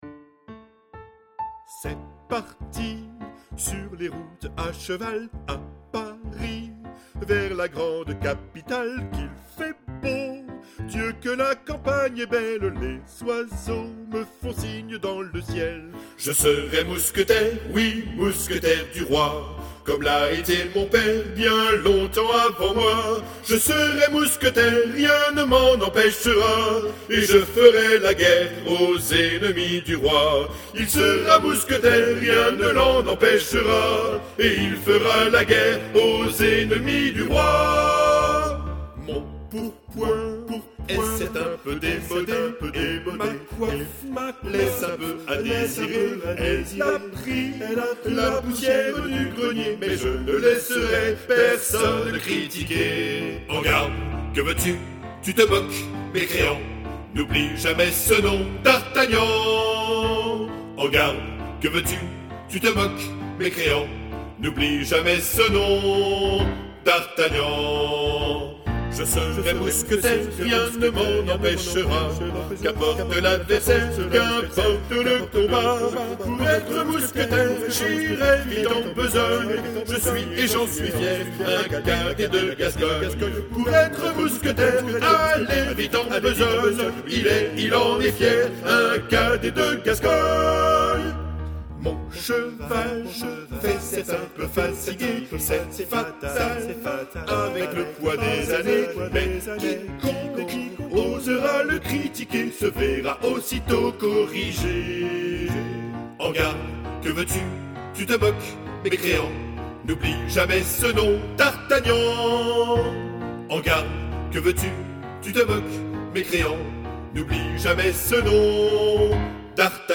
ATTENTION : ces fichiers audio comportent peu ou pas de nuances, il ne s'agit (normalement!) que des bonnes notes à la bonne place
avec la bonne durée le plus souvent chantées par des voix synthétiques plus ou moins agréables .